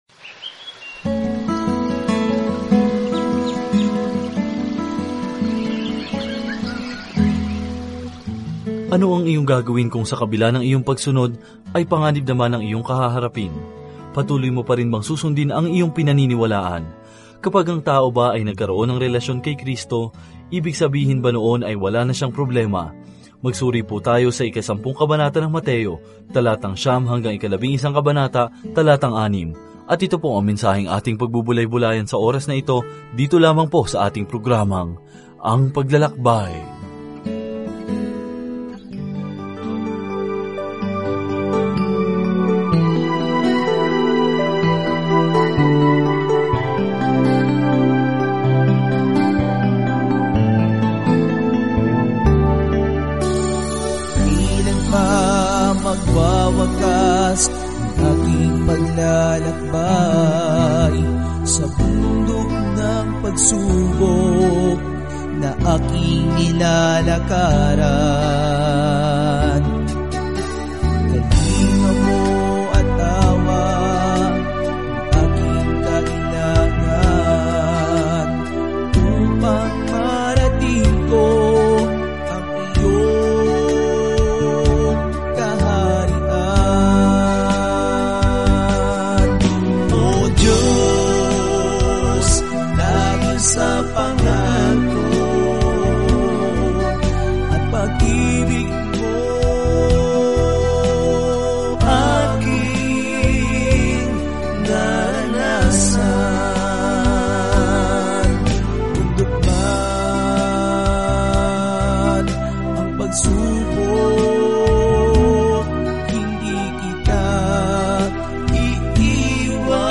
Banal na Kasulatan Mateo 10:9-42 Mateo 11:1-6 Araw 15 Umpisahan ang Gabay na Ito Araw 17 Tungkol sa Gabay na ito Pinatunayan ni Mateo sa mga Judiong mambabasa ang mabuting balita na si Jesus ang kanilang Mesiyas sa pamamagitan ng pagpapakita kung paano natupad ng Kanyang buhay at ministeryo ang hula sa Lumang Tipan. Araw-araw na paglalakbay sa Mateo habang nakikinig ka sa audio study at nagbabasa ng mga piling talata mula sa salita ng Diyos.